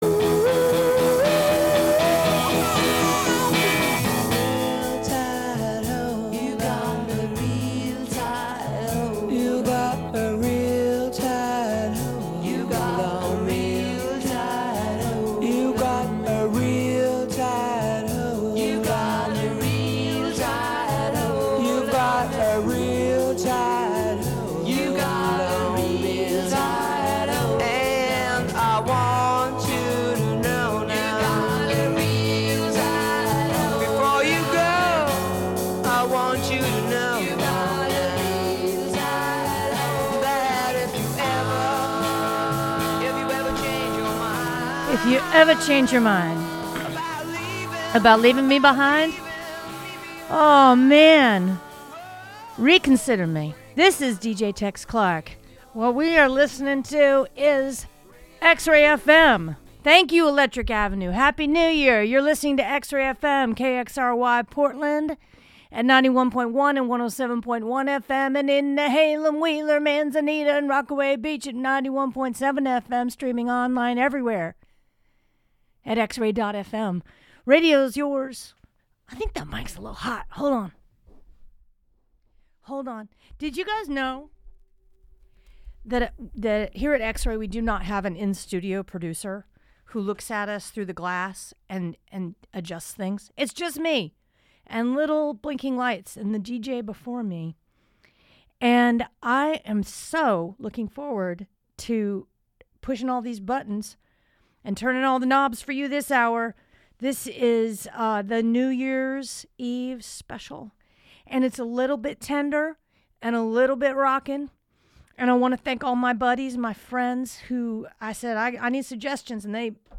Join the hunt for lost gems and new treasures every Wednesday at 7pm. We serve indie rock, global psychedelica, lo-fi, art punk, a particular sort of americana, Portland-centric, international pop, folkishness, and real rock and good radio.